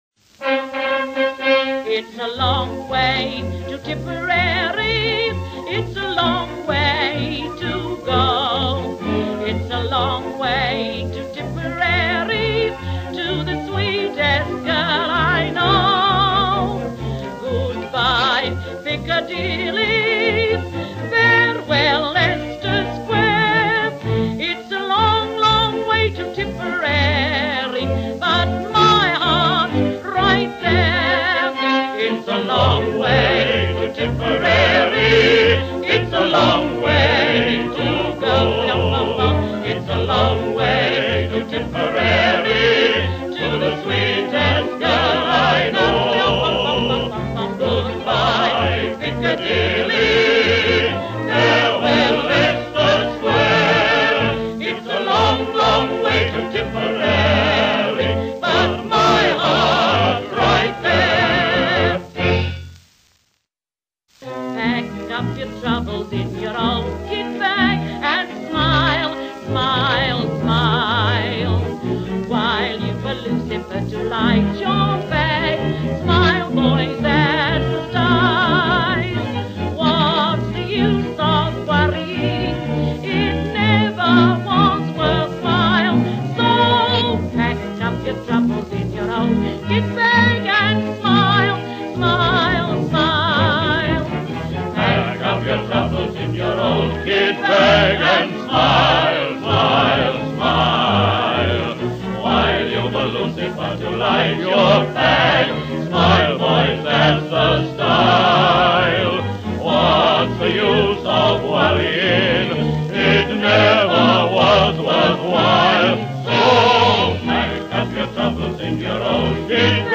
Sung by Florrie Forde, a popular Music Hall performer during the First World War, she re-recorded these two songs ten years after the war
Florrie-Forde-A-Long-Long-Way-To-Tipperary-Pack-Up-Your-Troubles-In-Your-Old-Kit-Bag-1929.mp3